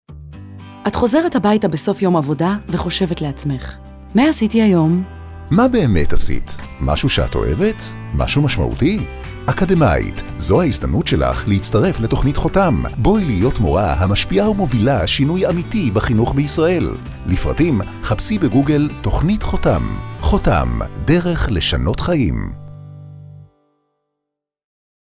תשדירי רדיו לדוגמה
Hotam-woman-final.mp3